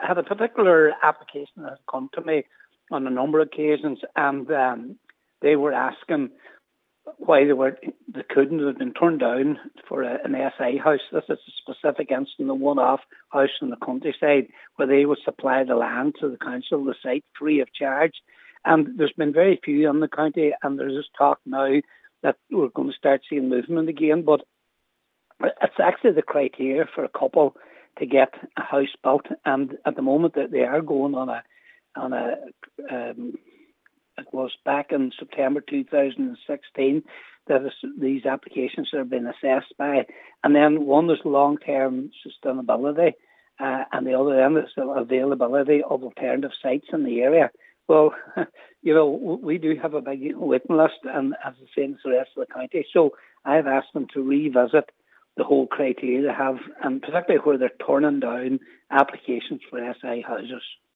Councillor Patrick McGowan believes the current criteria drawn up in 2016 is beyond outdated.